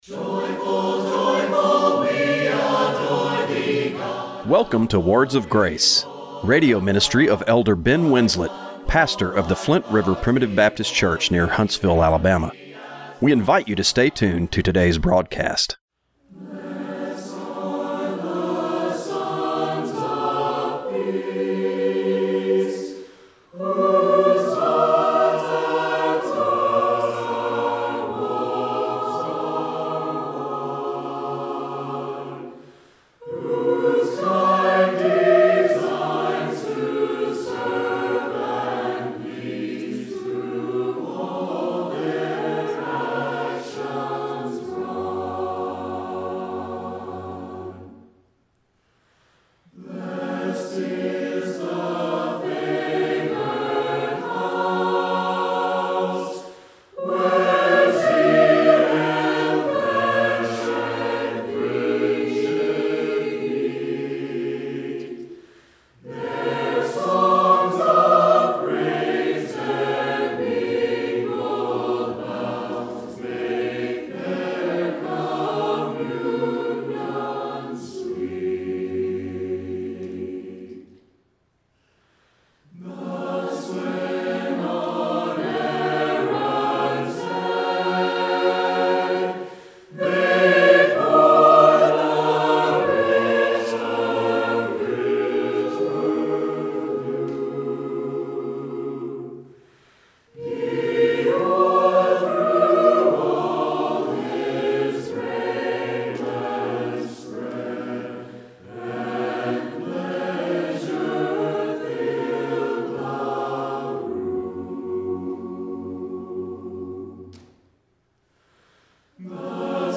Radio broadcast for March 23, 2025.